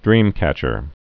(drēmkăchər, -kĕch-)